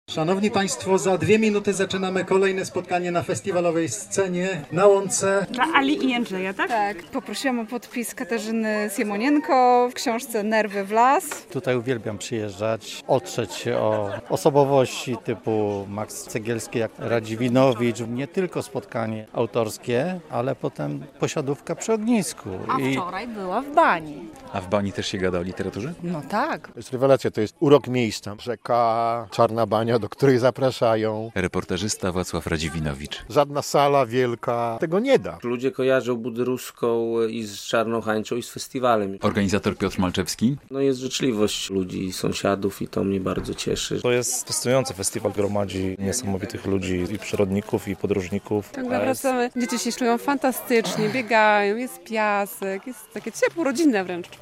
Festiwal literacki "Patrząc na Wschód" - relacja